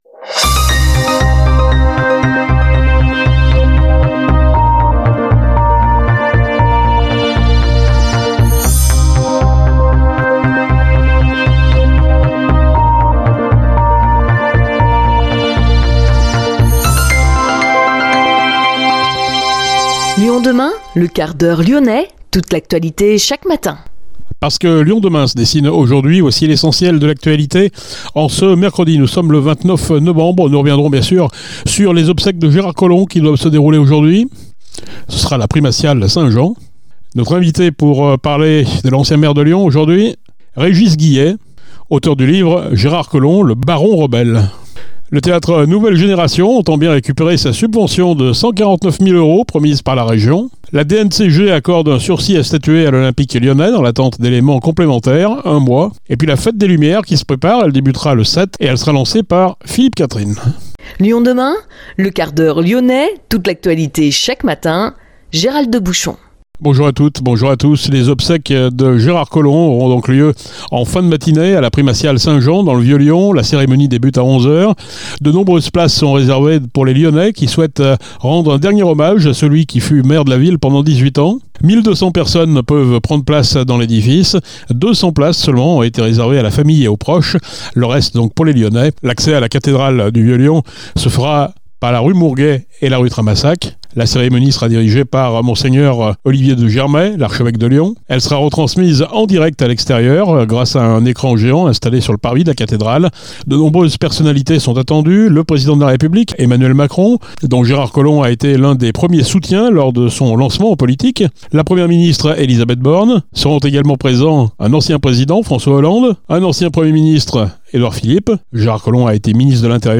Notre invité